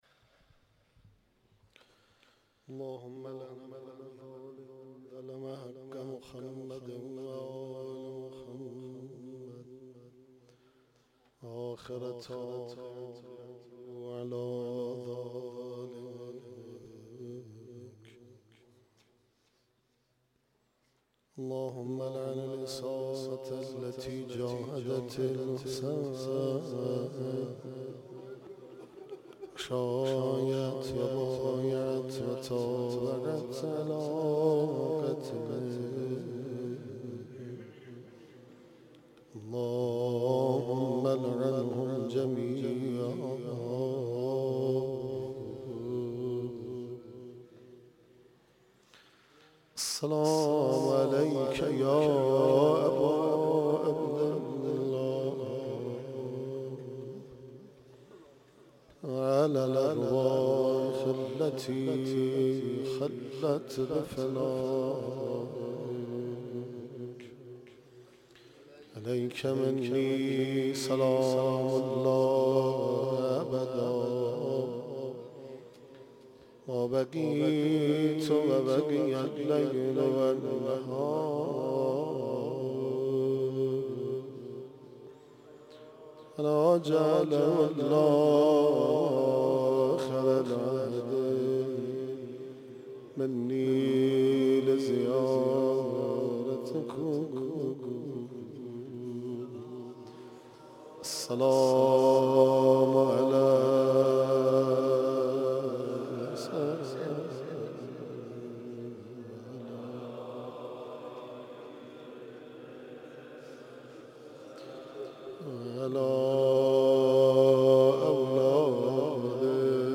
چهارمین شب از مـــراســم عـــزاداری دهــه اول مـــحــرم الـحــرام
مرثیه سرایی
روضه